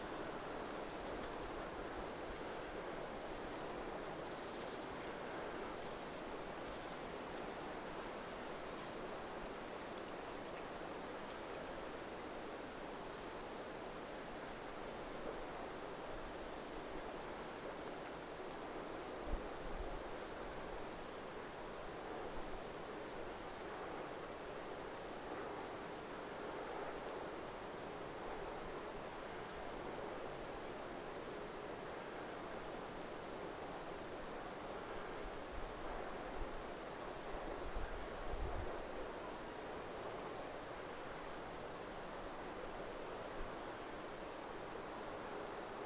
ti-puukiipijän ti-ääntelyä
ti-puukiipijan_aantelya.mp3